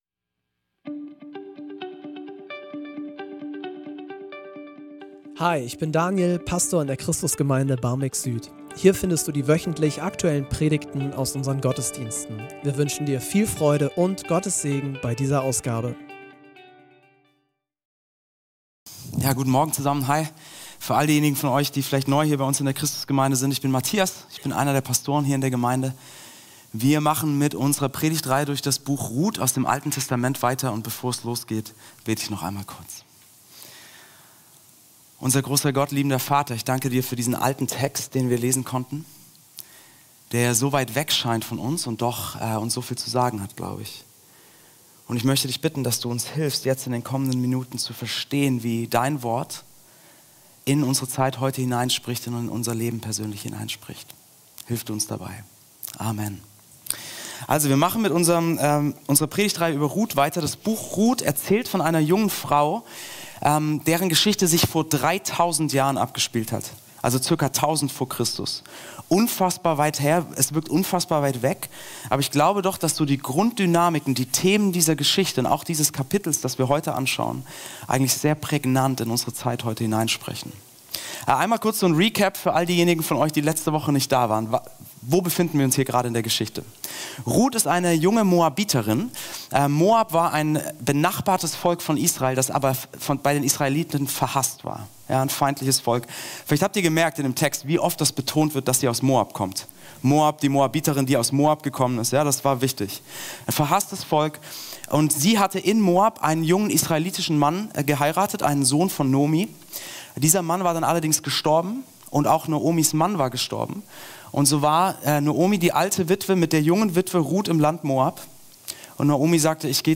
Predigtreihe